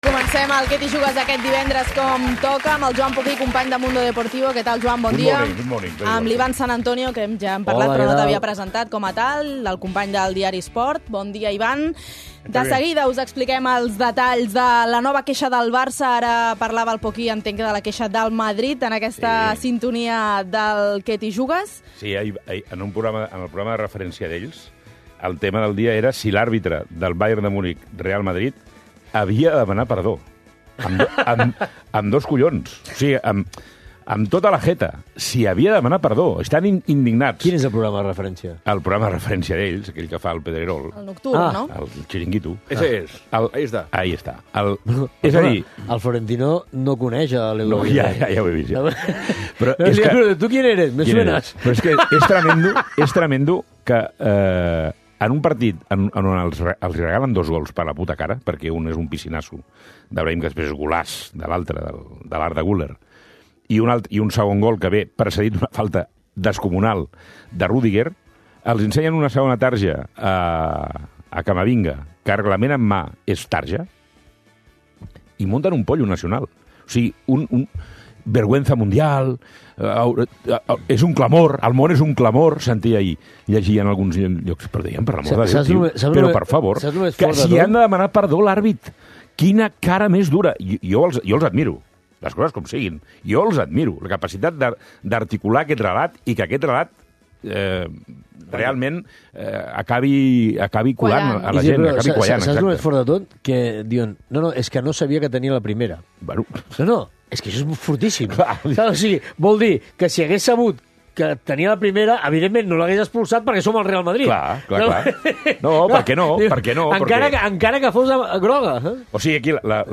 Al 'Què t'hi Jugues!' fem tertúlia de l'actualitat futbolística